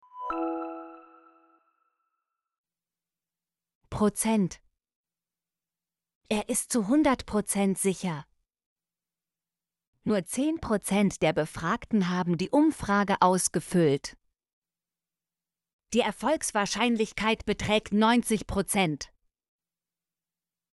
prozent - Example Sentences & Pronunciation, German Frequency List